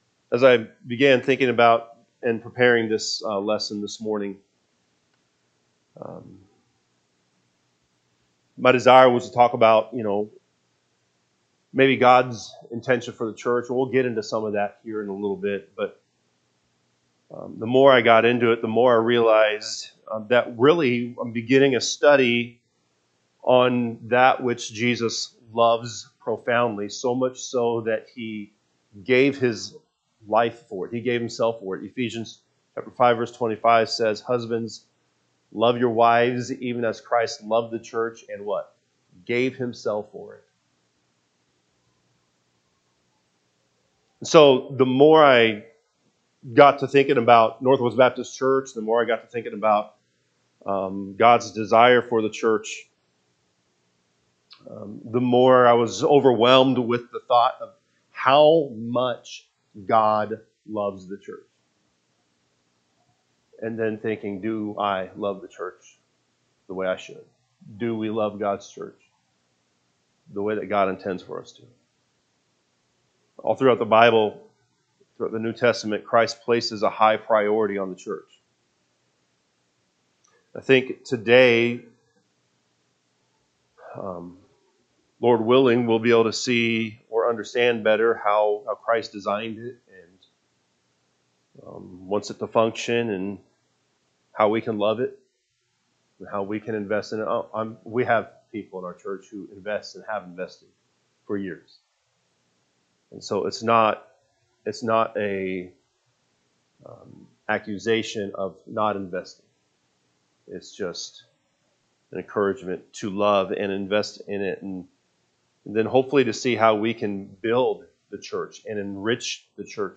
September 7, 2025 Adult Bible Study